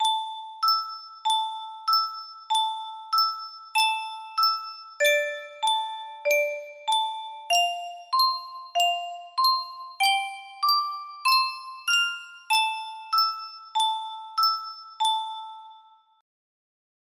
Yunsheng Custom Tune Music Box - Unknown Tune 8 music box melody
Full range 60